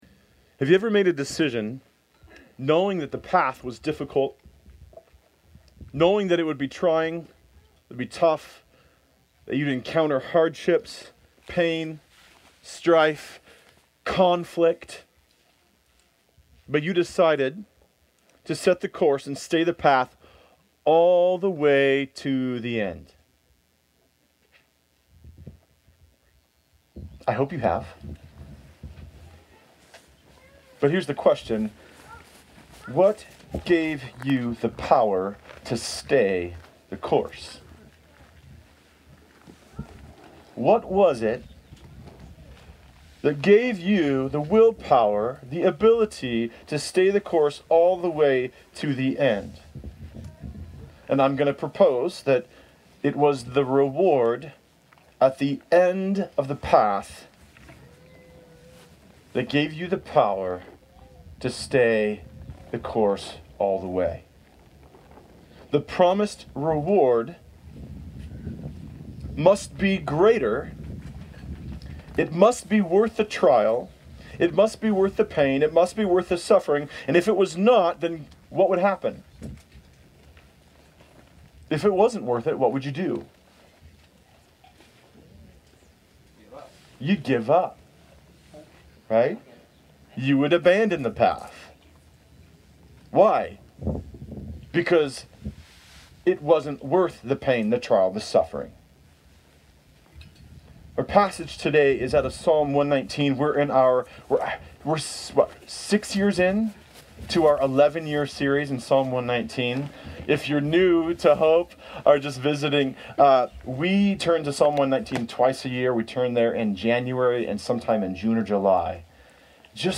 Church on the Farm 2024
Service Type: Sunday Service